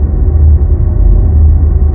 AmbDroneR.wav